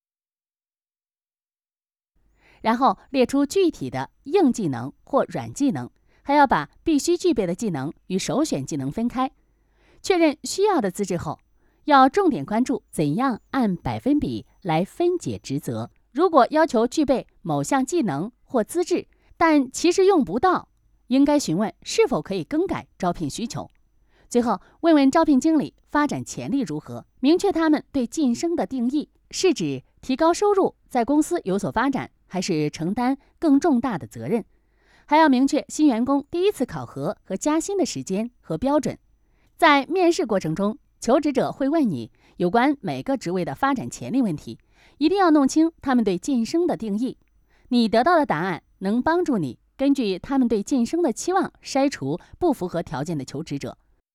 Chinese_Female_042VoiceArtist_5Hours_High_Quality_Voice_Dataset